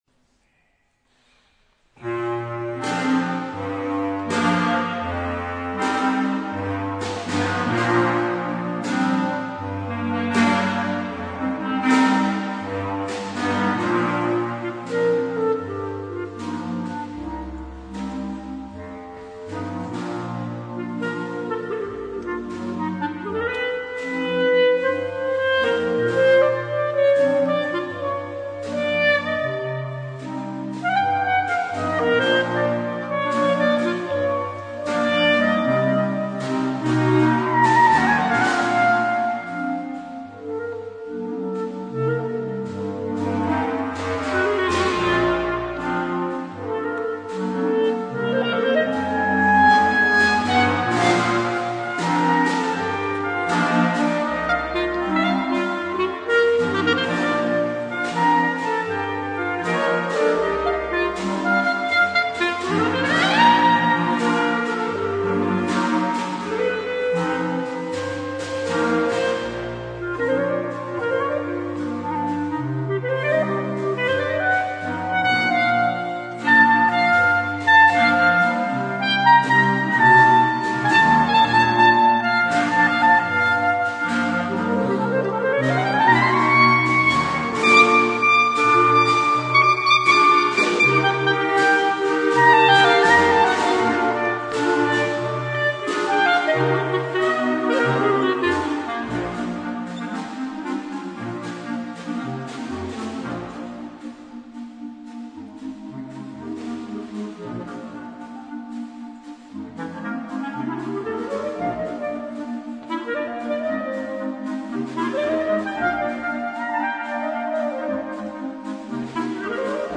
for solo clarinet and clarinet choir